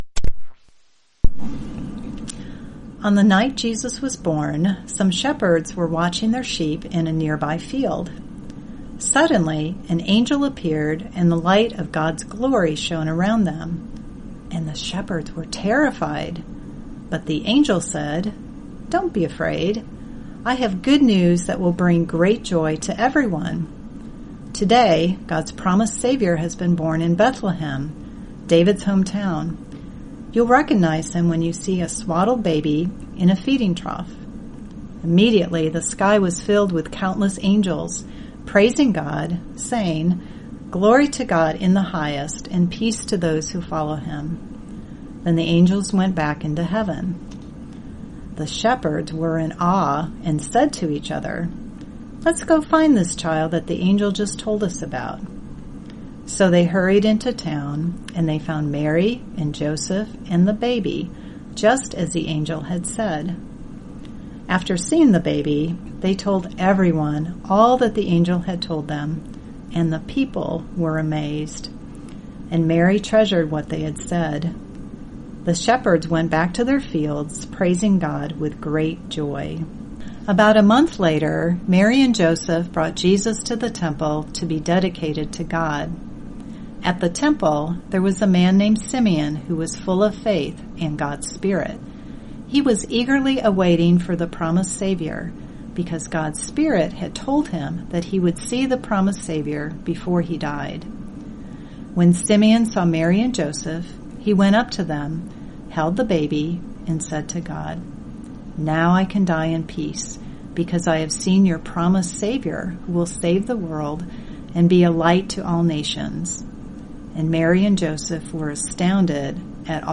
This Advent season, anticipate the celebration of Christmas with oral Bible stories. Listen to a story based on Scripture, reflect on its themes and journal with the Lord.